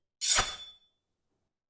SWORD_16.wav